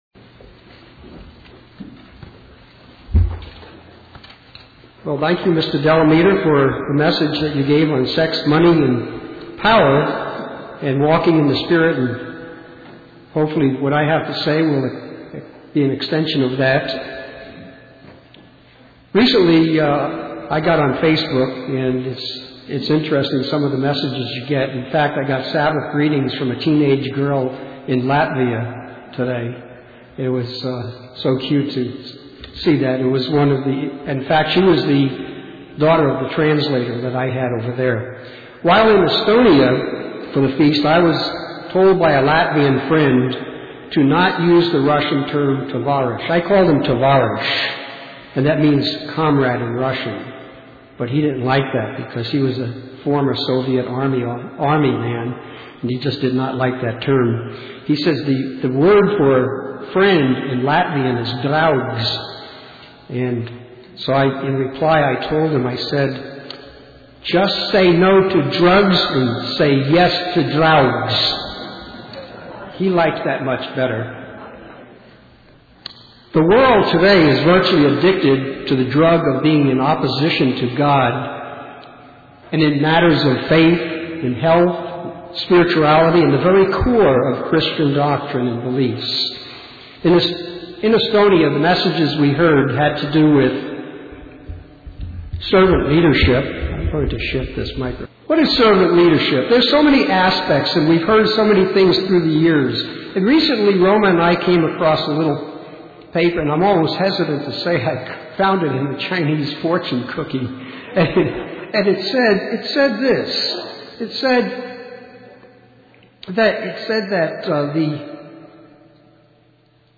UCG Sermon Notes Title: The Heart of a Servant Introduction: Tere Tulemast!